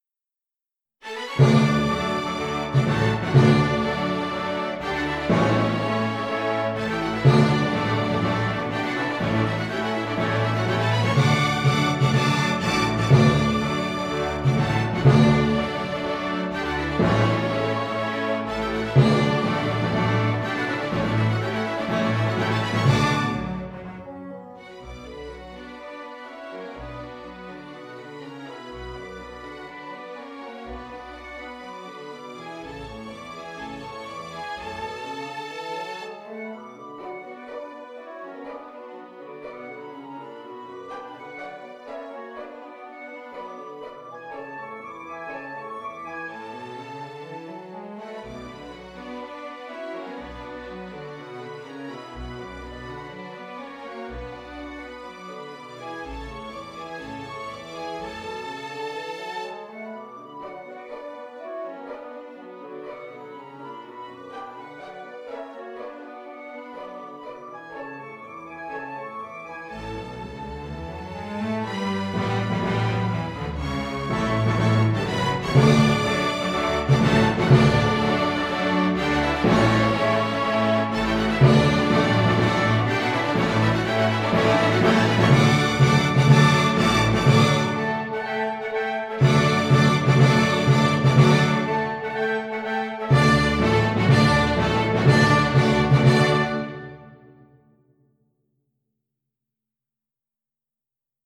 marches
orchestrated now all 5 marches
Beeth_Biamonti_381-5_orch.mp3